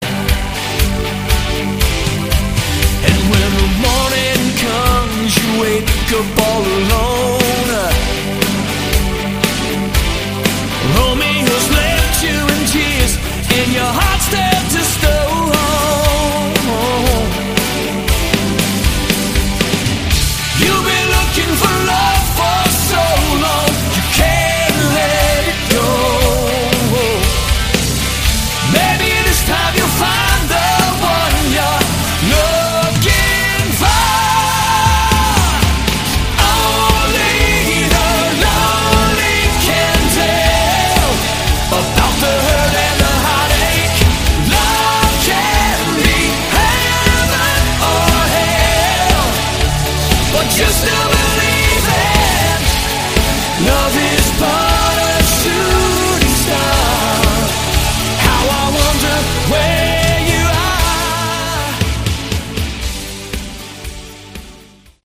Category: AOR / Melodic Rock